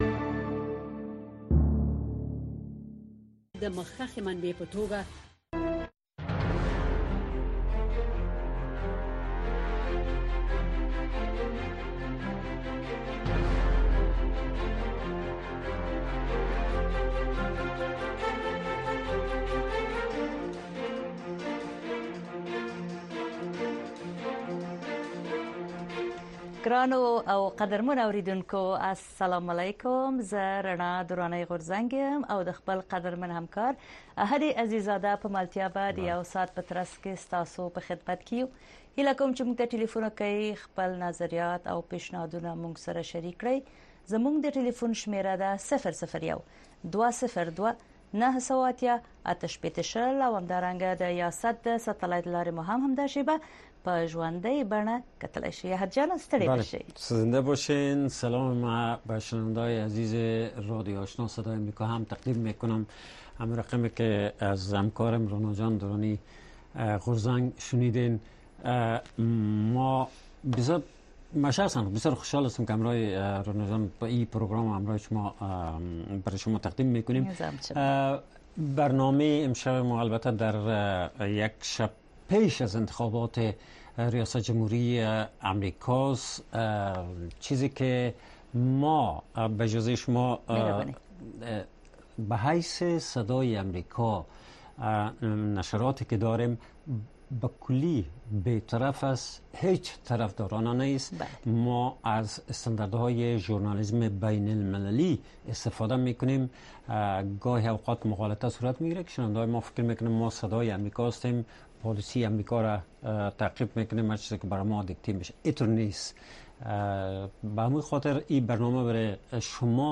در برنامۀ صدای شما، شنوندگان رادیو آشنا صدای امریکا مستقیماً با ما به تماس شده و نگرانی‌ها، دیدگاه‌ها، انتقادات و شکایات شان را با گردانندگان و شنوندگان این برنامه در میان می‌گذارند. این برنامه به گونۀ زنده از ساعت ۹:۳۰ تا ۱۰:۳۰ شب به وقت افغانستان نشر می‌شود.